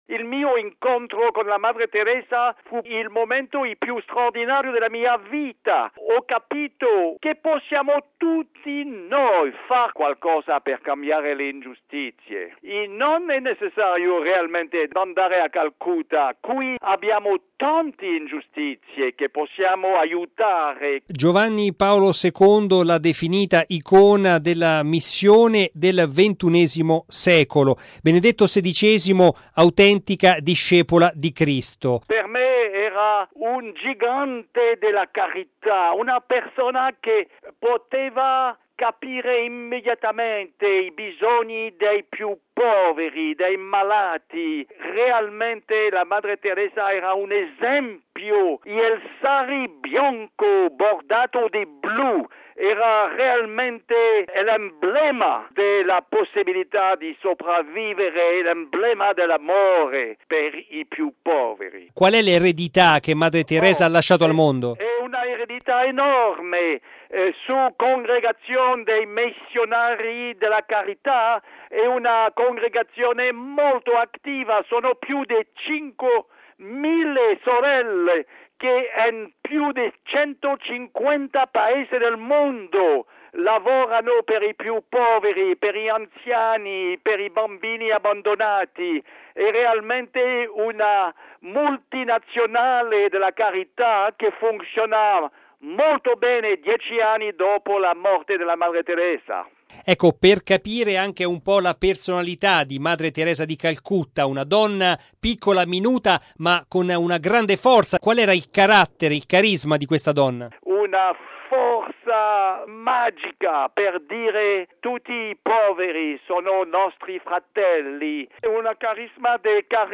“Un’icona della Carità” così viene definita Madre Teresa dallo scrittore francese Dominique Lapierre (autore del recente Libro "Un arcobaleno nella notte", edito da Il Saggiatore, il cui incasso sarà interamente devoluto alle missioni presenti a Calcutta) che incontrò più volte Madre Teresa. Ecco la sua testimonianza nell’intervista